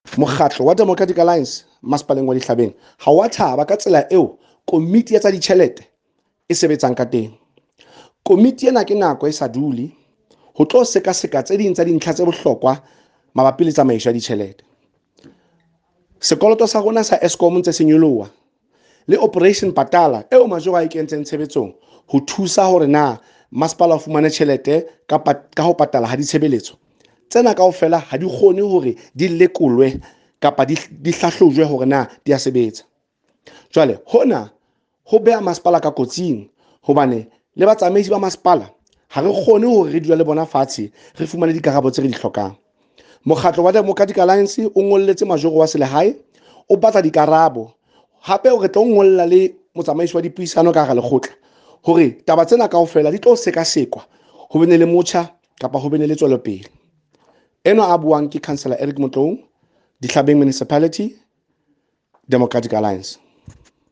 Sesotho soundbites by Cllr Eric Motloung.
Sotho-voice-7.mp3